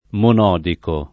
[ mon 0 diko ]